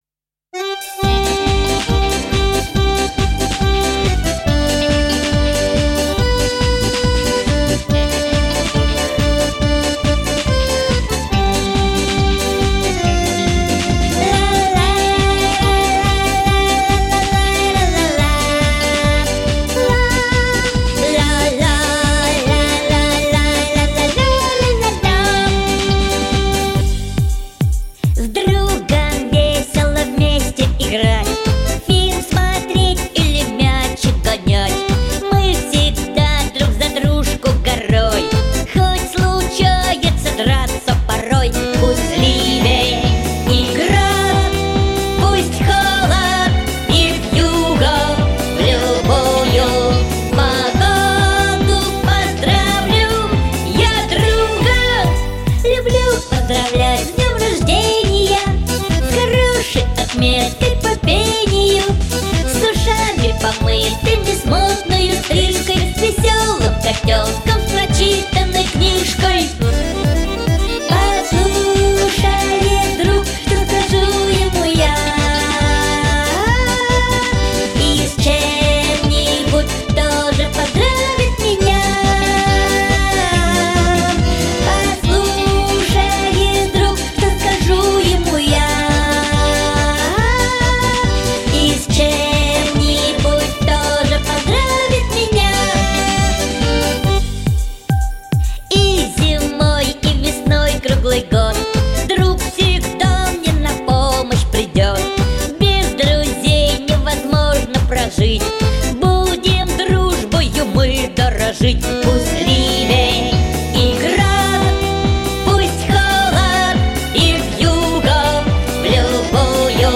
Детская песня
Детские песни